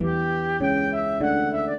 flute-harp
minuet2-12.wav